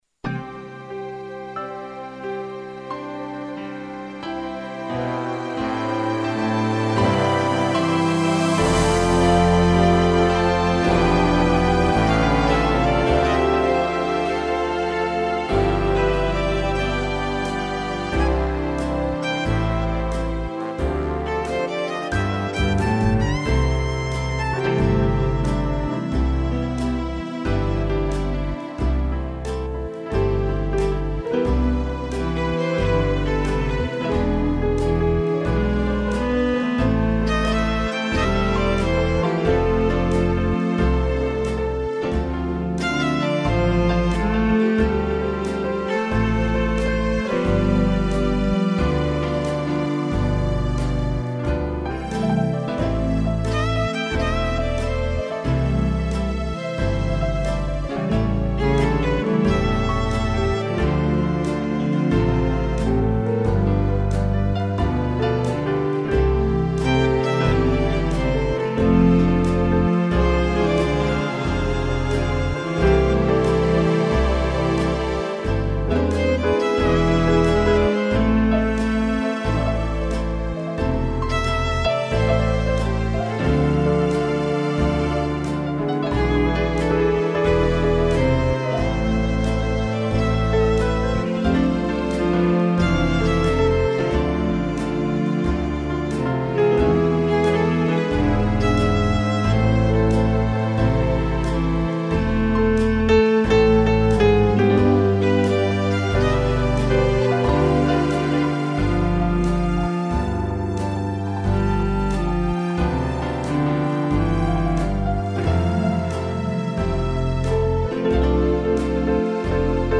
violino e cello
(instrumental)